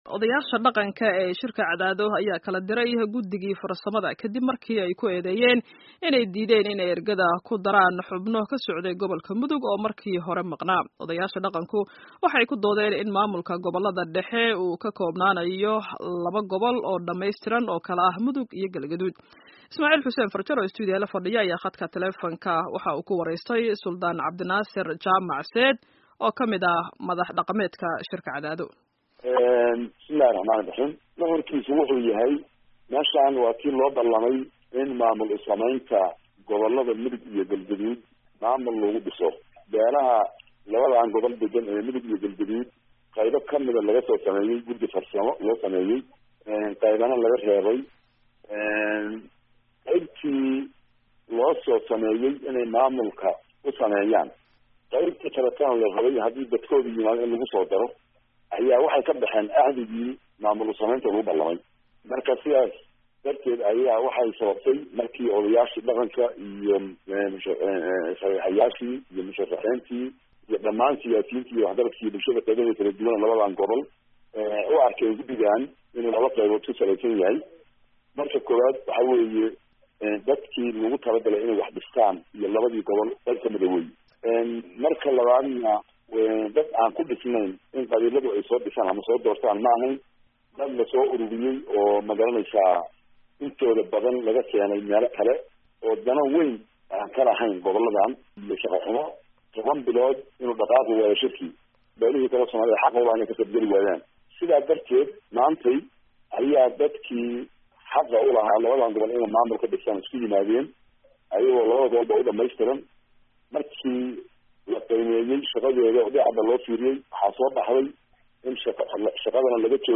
Dhegayso: Waraysi ku saabsan shirka Cadaado